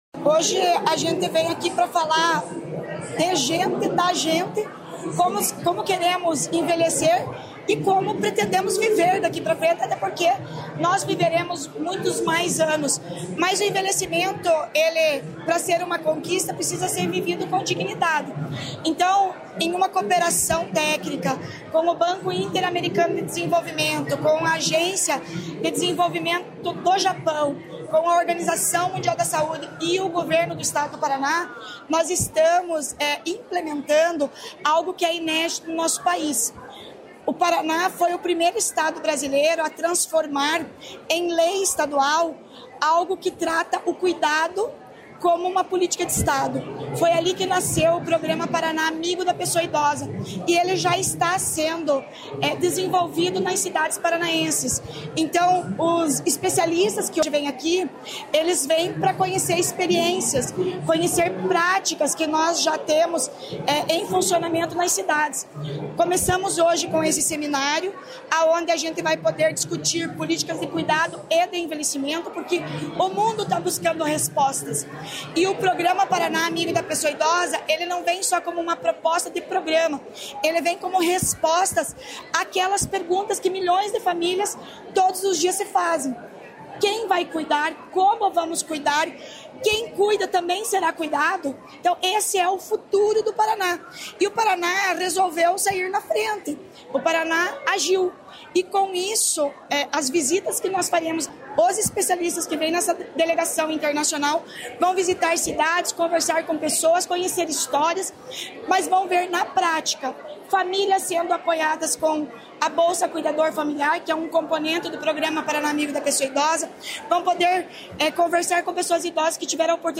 Sonora da secretária de Estado da Mulher, Igualdade Racial e Pessoa Idosa, Leandre Dal Ponte, sobre Cadastro do Cuidador | Governo do Estado do Paraná